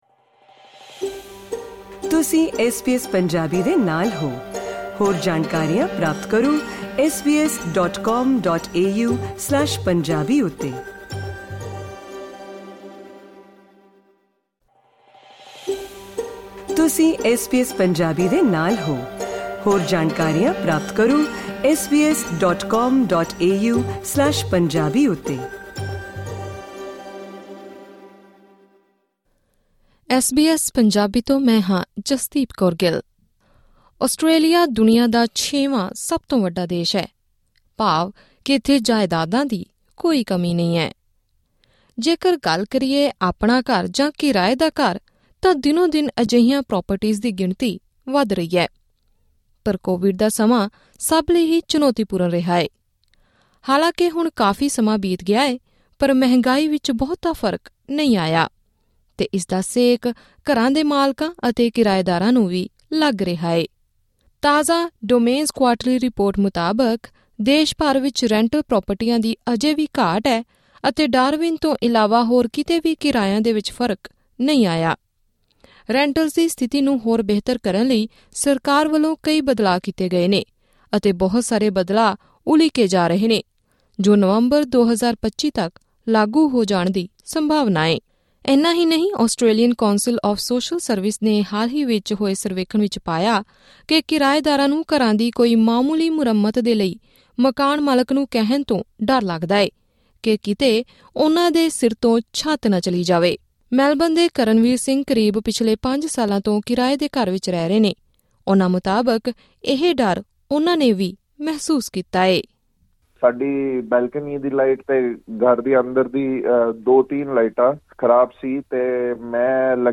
Click on the audio icon to listen to the full interview in Punjabi.